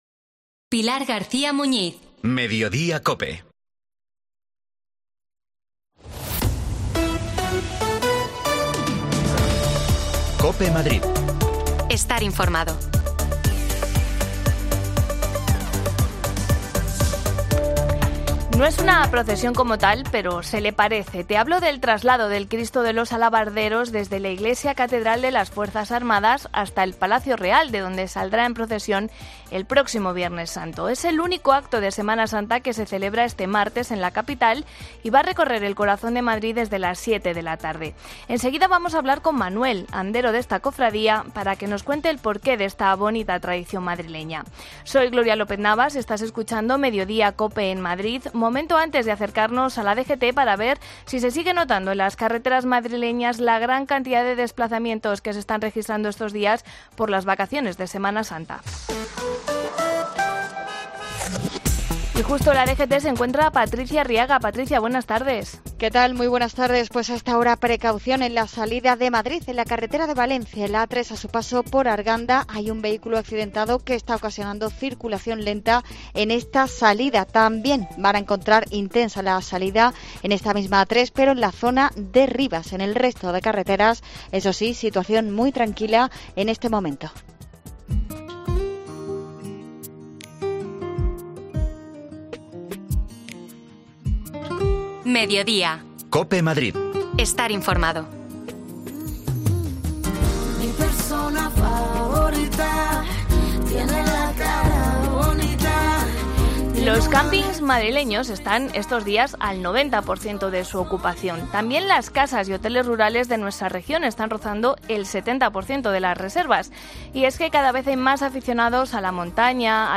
La cita de este Martes Santo en Madrid es el traslado del Cristo de los Alabarderos de la iglesia catedral de las Fuerzas Armadas hasta el Palacio Real. Hablamos con un andero.